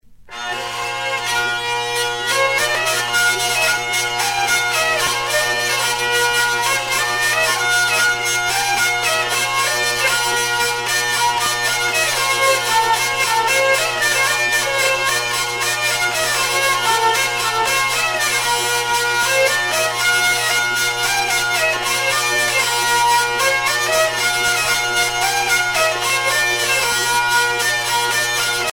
Bretagne
danse : violette
Sonneurs de vielle traditionnels